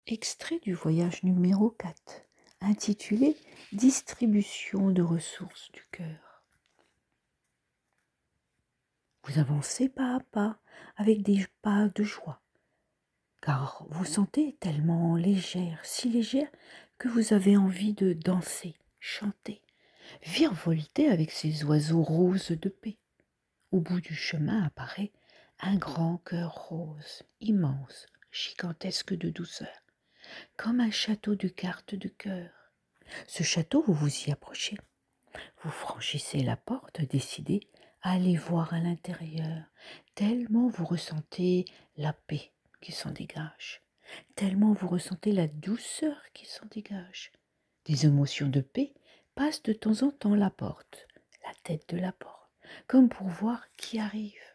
26.35 min de voyage méditatif